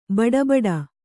♪ baḍa baḍa